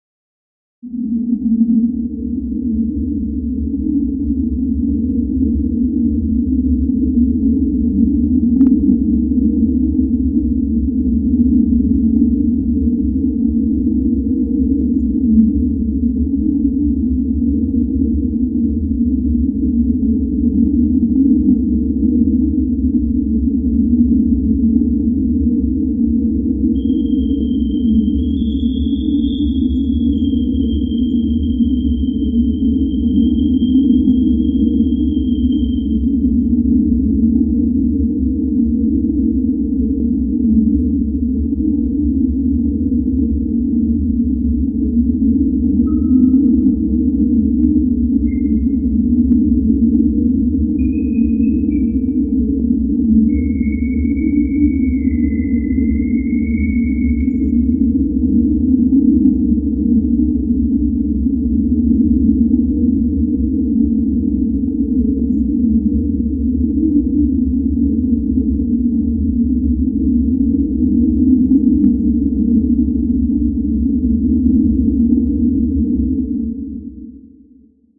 描述：环境，紧张的音景和隆隆声基于正在运行的火车内的环境/声场麦克风录音。
Tag: 地铁 电子 声景 混响 氛围 处理 奇怪 隆隆声 电影 列车 介绍 高铈 戏剧 科幻 噪声 大气 地铁